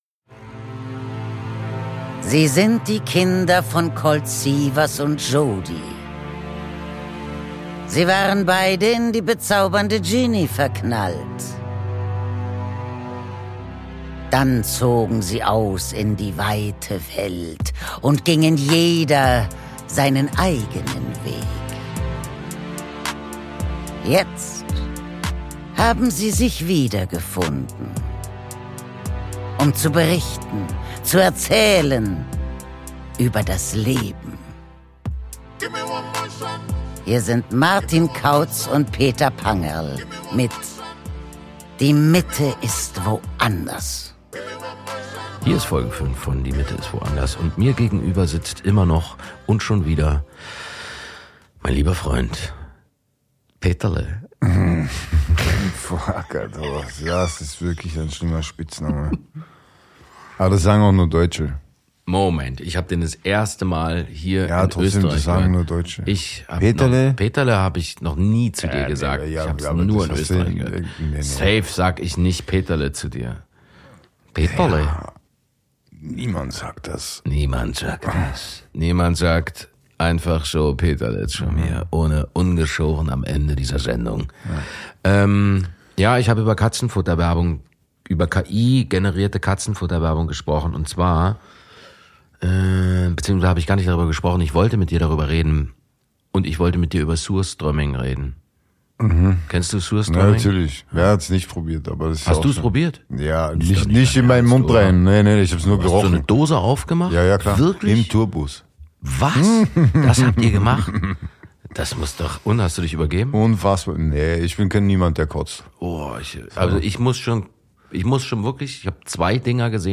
Beide Freunde müssen feststellen, dass das Leben ohne Schall & Rauch ziemlich nüchtern ist. Aus dem 25h Hotel im Wiener Museumsquartier